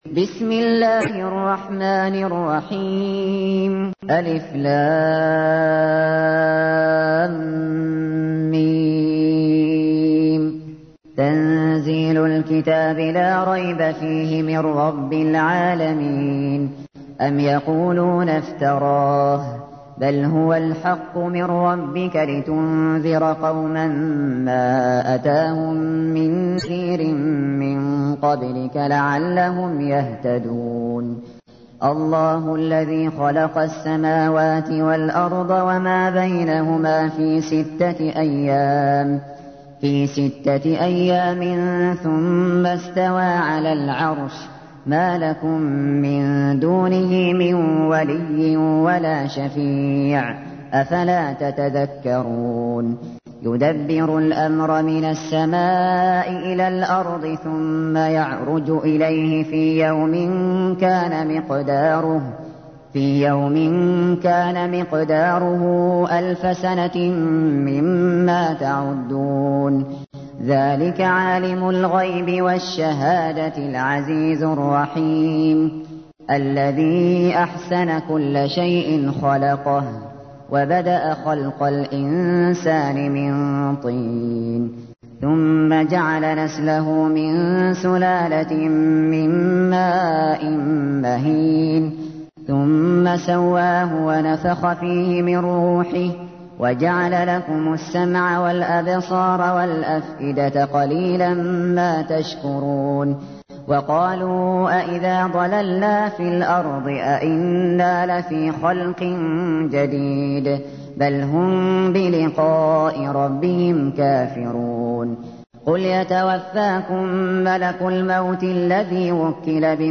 تحميل : 32. سورة السجدة / القارئ الشاطري / القرآن الكريم / موقع يا حسين